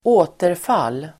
Uttal: [²'å:terfal:]